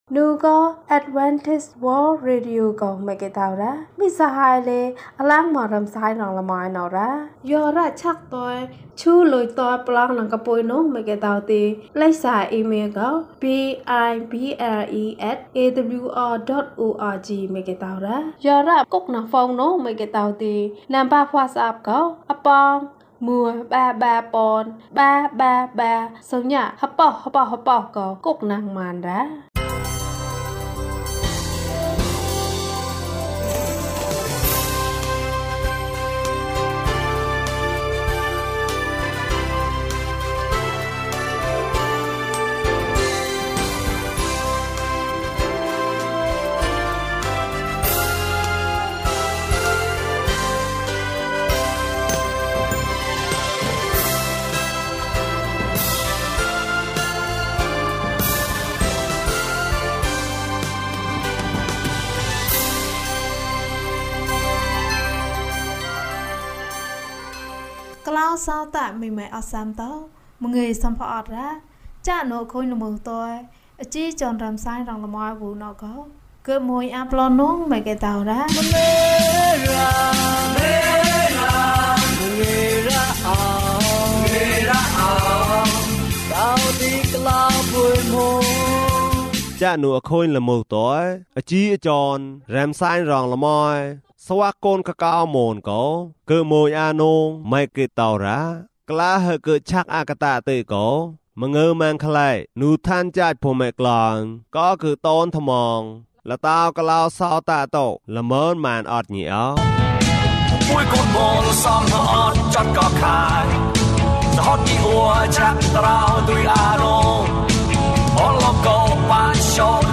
သိုးထိန်း။ ကျန်းမာခြင်းအကြောင်းအရာ။ ဓမ္မသီချင်း။ တရားဒေသနာ။